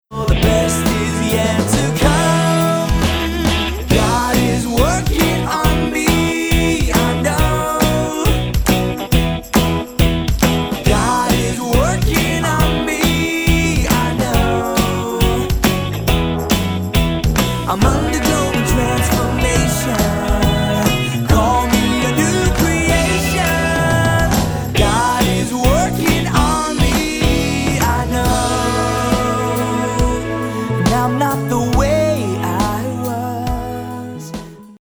energetic praise and worship music